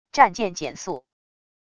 战舰减速wav音频